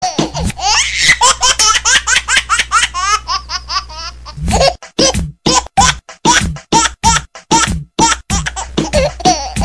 Babbling Baby Remix 2 - Botón de Efecto Sonoro